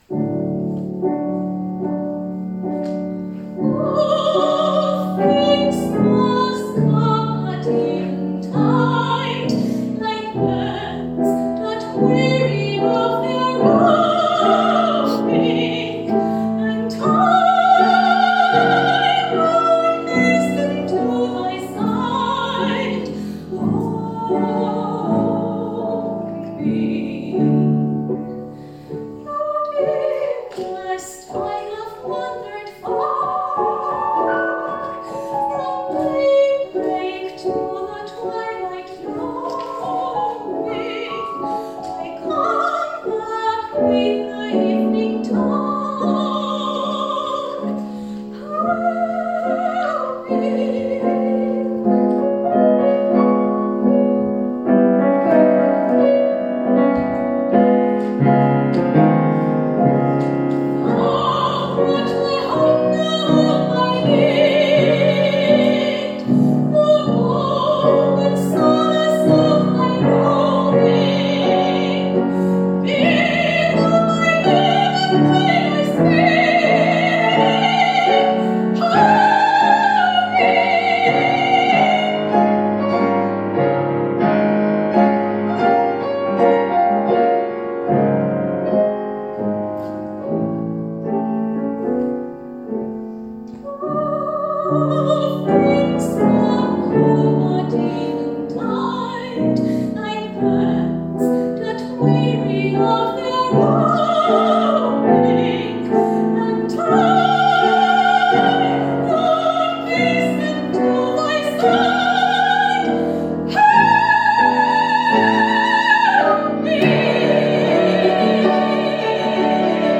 Soprano
Piano; Benefit Concert for Women in Iran and the Victims of the Earthquakes in Syria and Turkey, Bösendorfersaal, Mozarteum, February 28, 2023